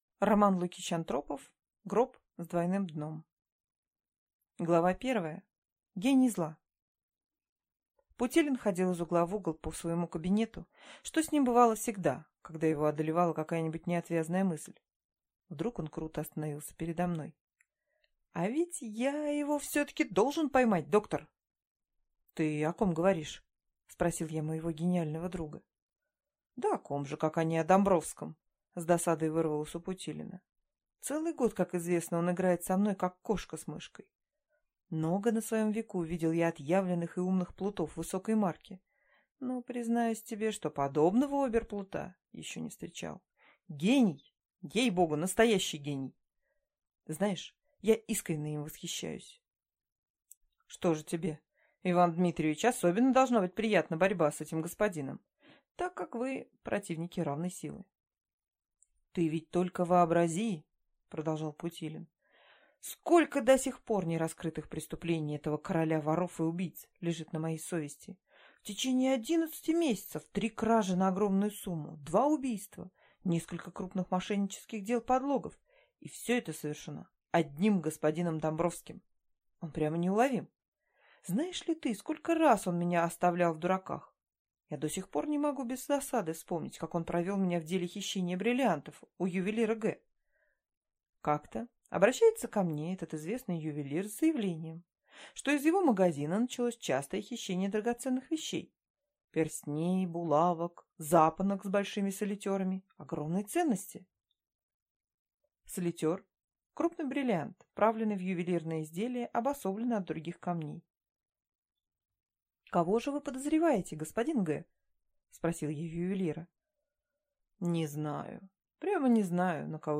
Аудиокнига Гроб с двойным дном | Библиотека аудиокниг